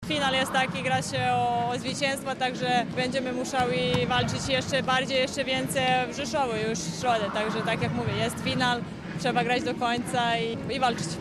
mówiła jeszcze po meczu w Łodzi